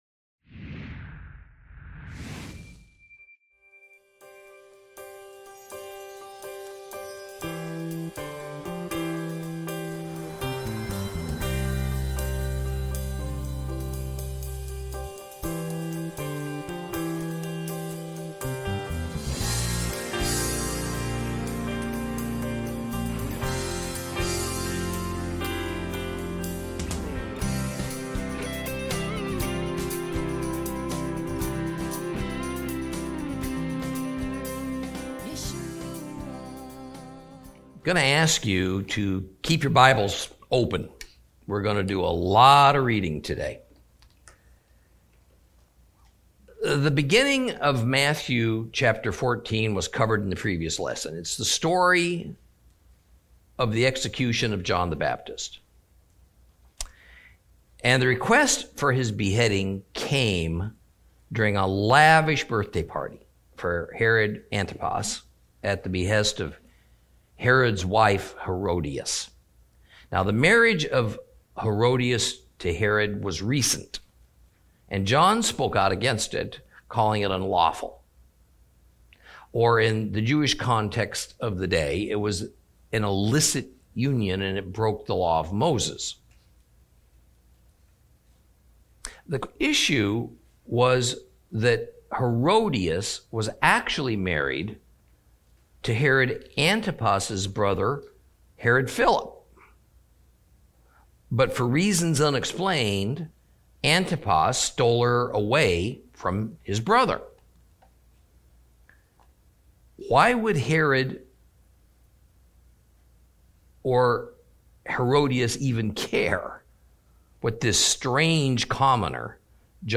Lesson 52 Ch14 - Torah Class